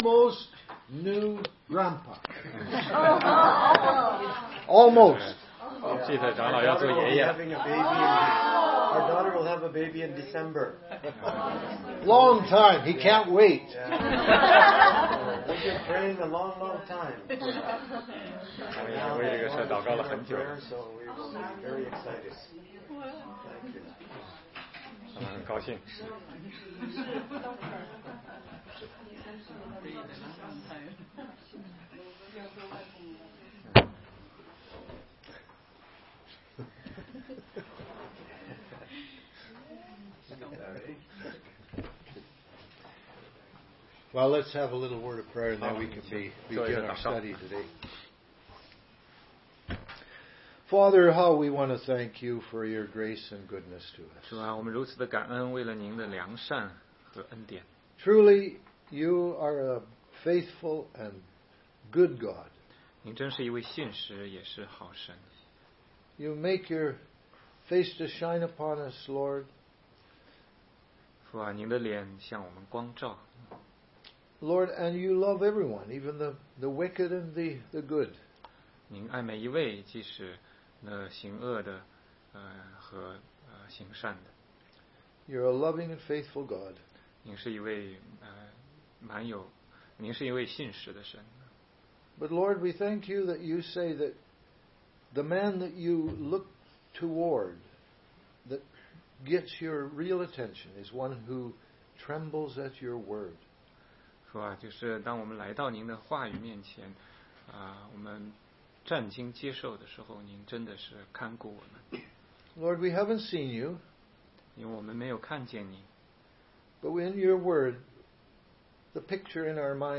16街讲道录音 - 约翰福音10章救恩永不会失去系列之四